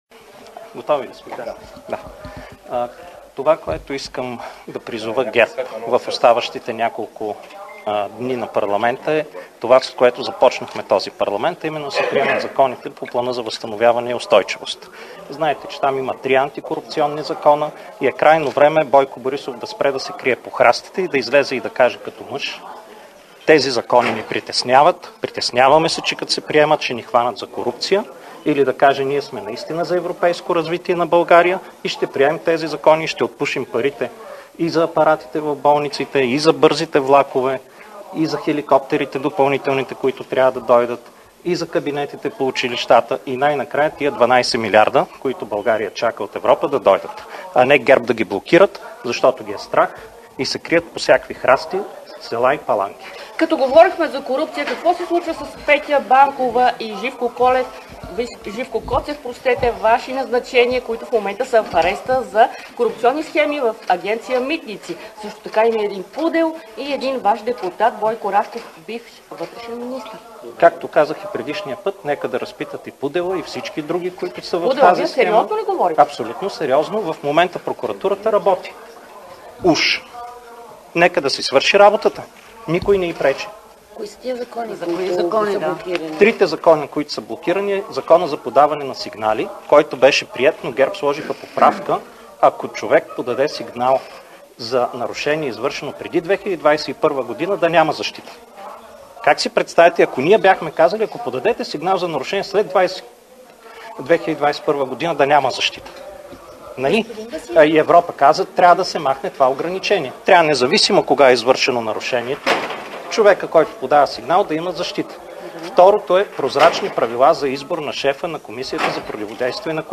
9.05 - Заседание на Народното събрание.
- директно от мястото на събитието (Народното събрание)